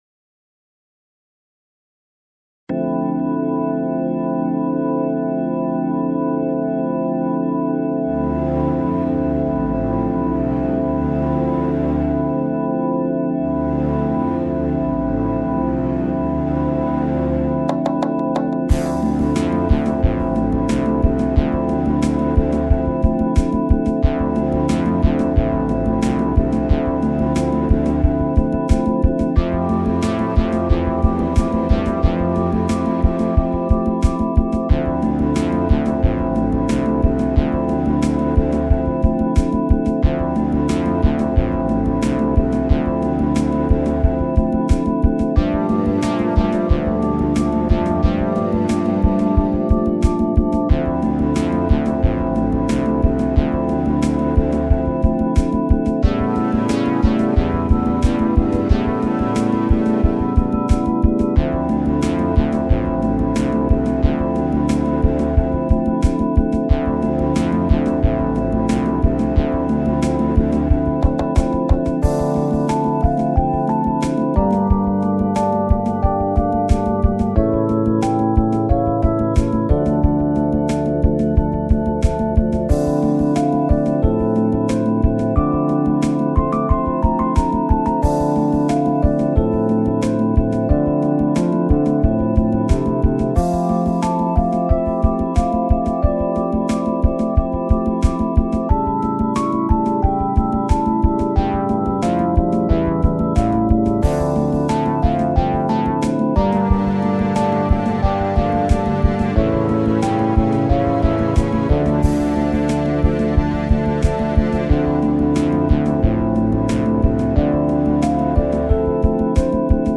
dance/electronic
Prog rock